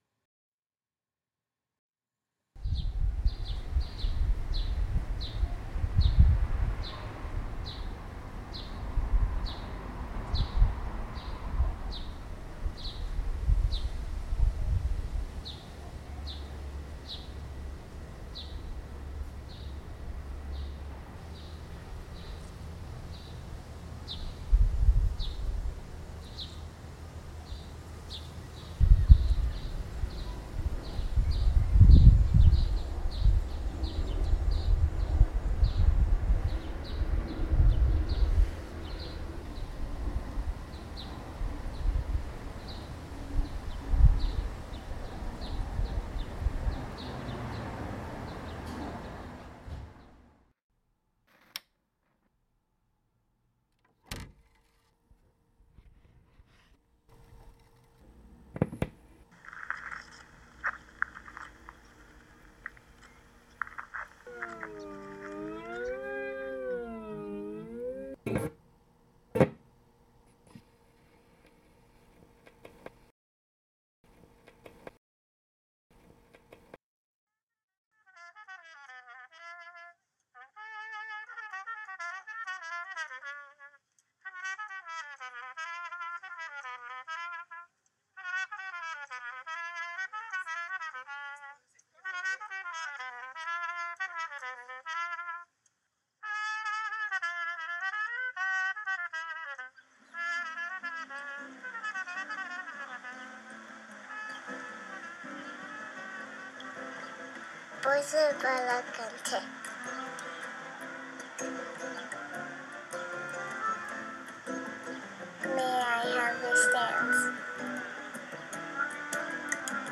Roman buskers reimagined